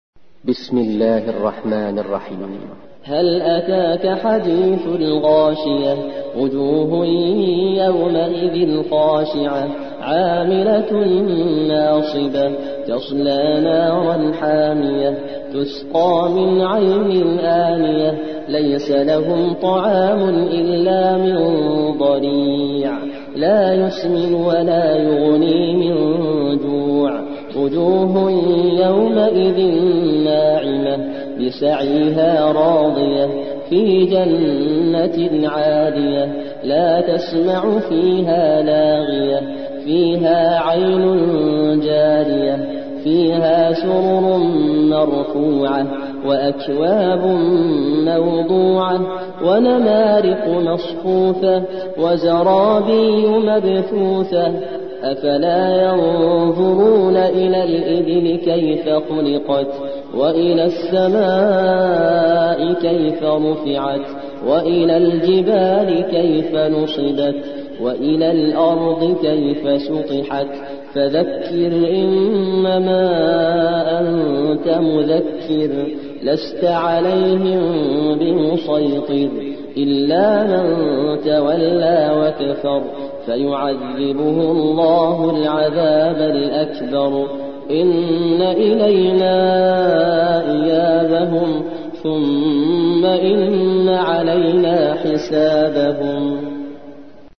88. سورة الغاشية / القارئ